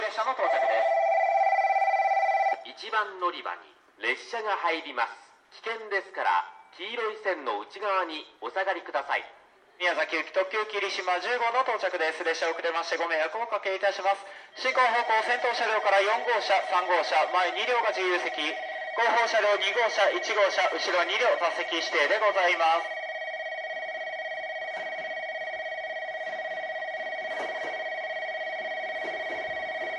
この駅では接近放送が設置されています。
１番のりば日豊本線
接近放送特急きりしま10号　宮崎行き接近放送です。